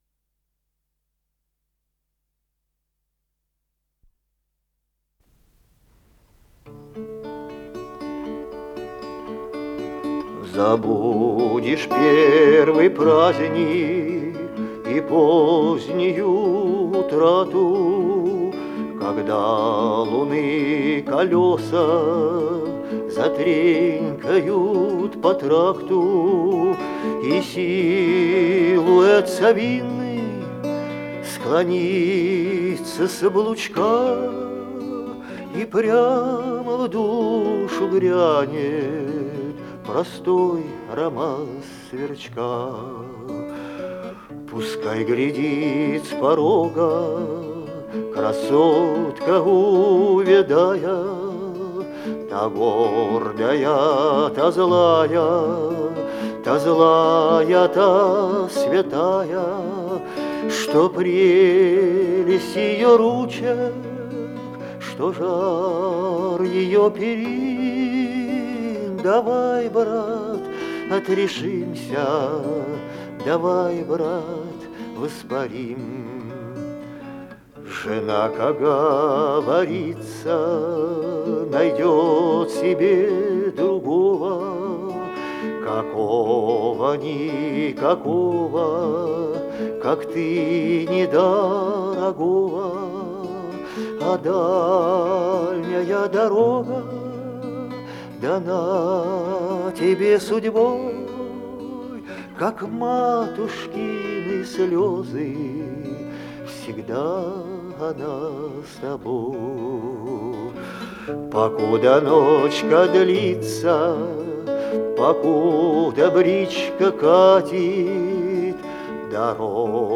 с профессиональной магнитной ленты
пение в собственном сопровождении на гитаре
ВариантДубль моно